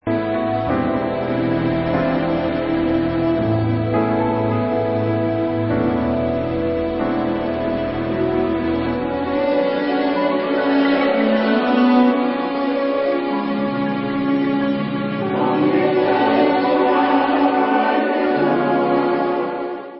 sledovat novinky v oddělení Alternative Rock